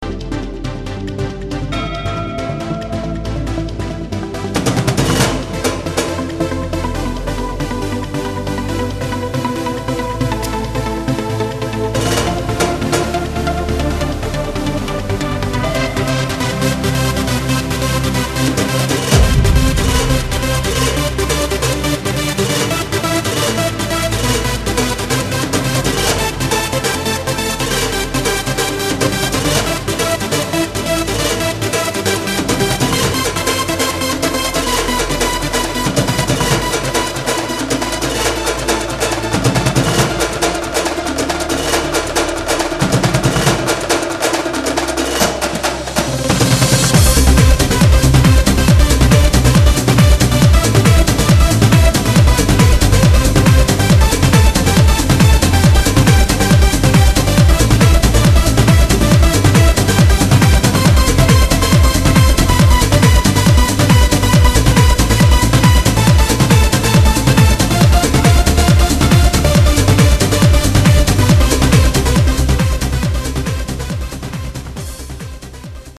Оба виртуазно играют на барабанах.